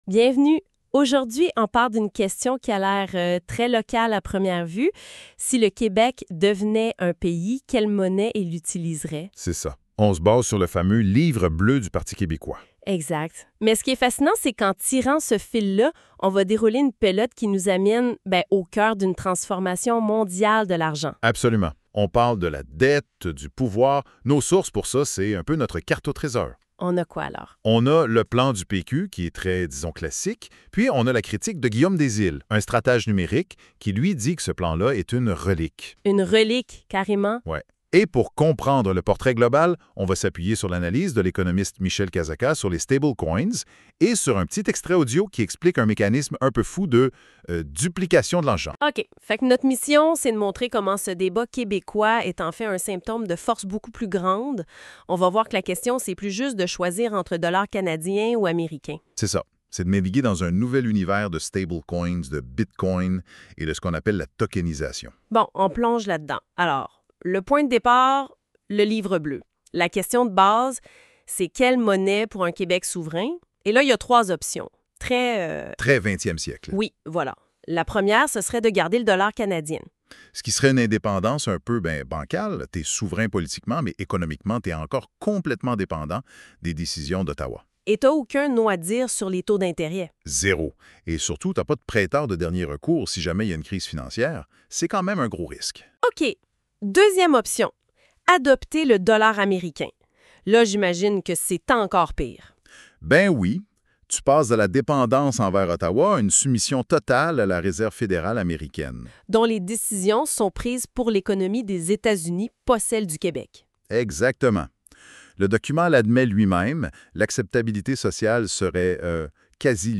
🎧 Écoutez une converse podcast en français 🇫🇷. qui résume cet article :